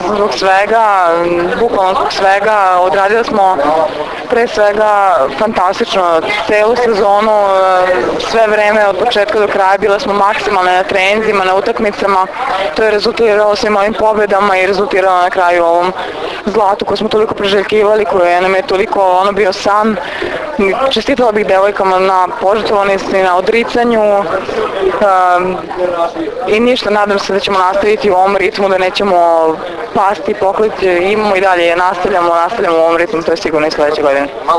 IZJAVA JOVANE BRAKOČEVIĆ